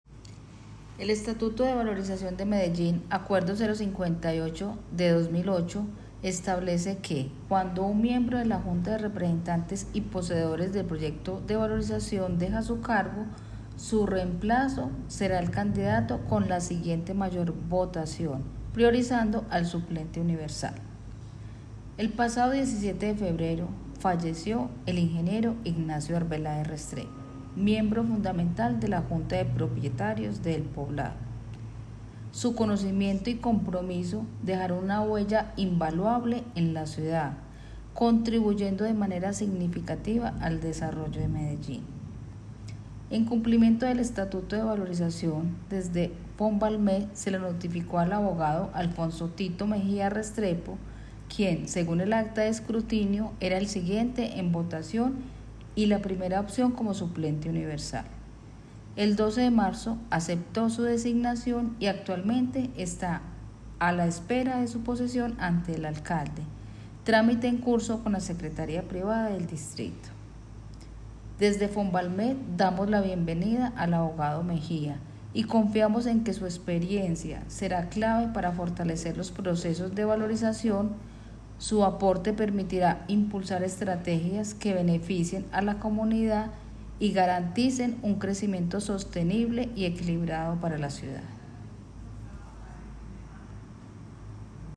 Palabras de Angélica María Arias Loza, directora de Fonvalmed